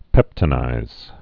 (pĕptə-nīz)